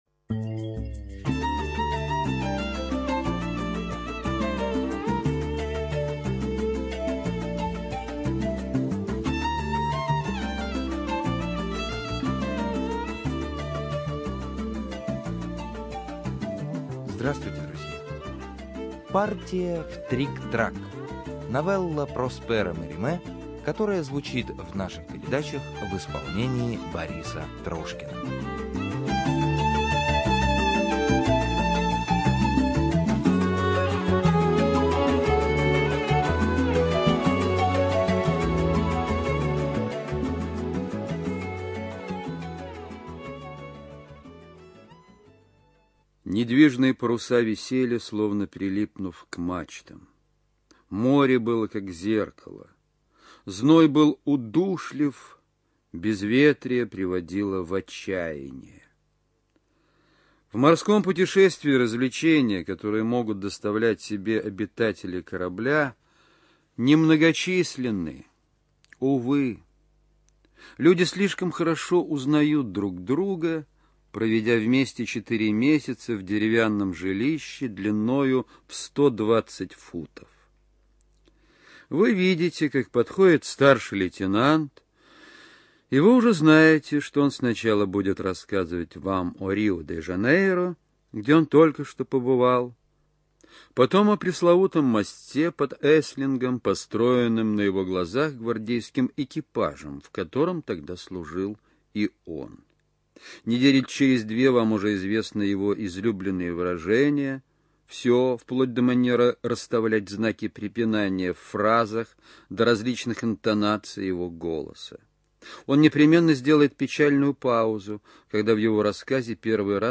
Студия звукозаписиРадио 4